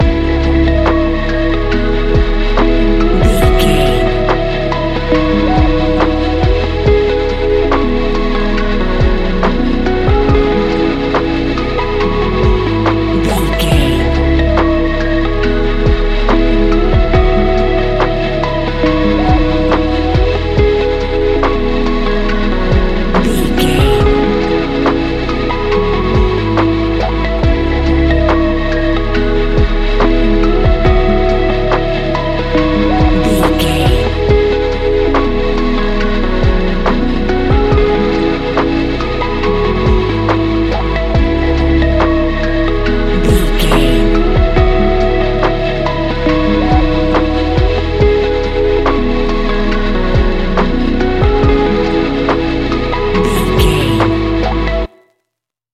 Ionian/Major
C♯
chilled
laid back
Lounge
sparse
new age
chilled electronica
ambient
atmospheric